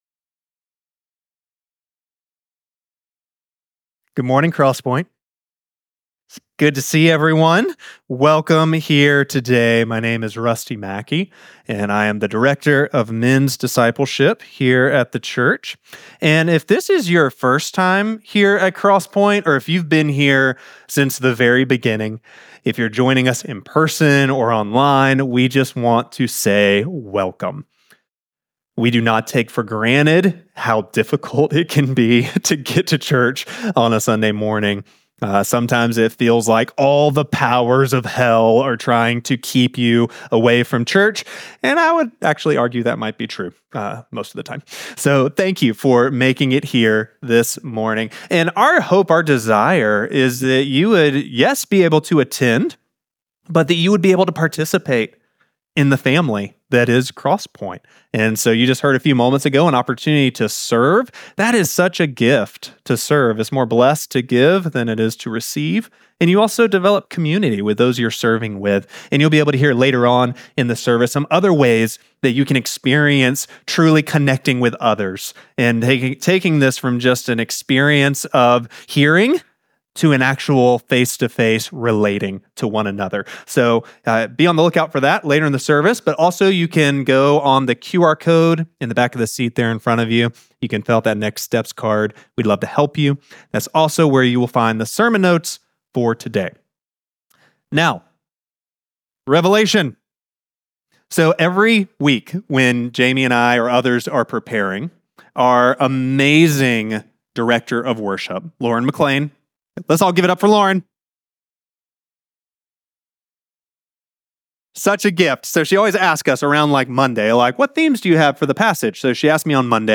Week 6 of our series Jesus Wins: A Study of Revelation. This sermon comes from Revelation chapters 8-9.